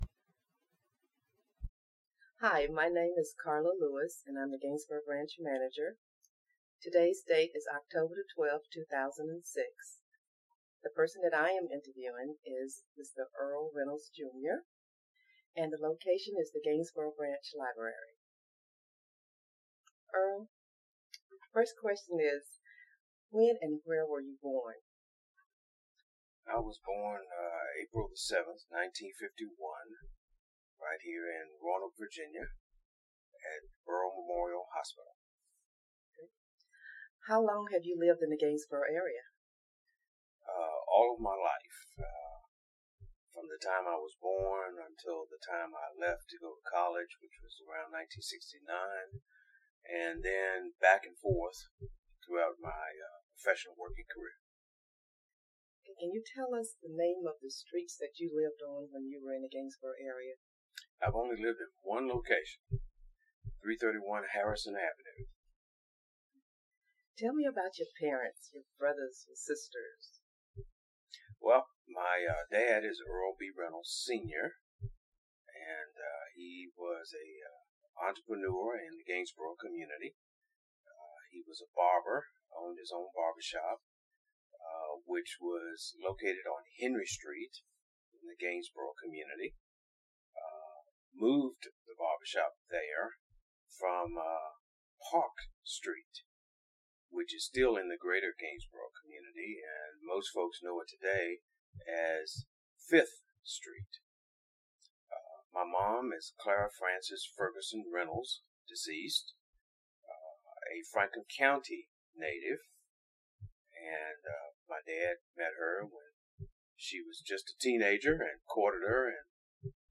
Location: Gainsboro Branch Library
Neighborhood Oral History Project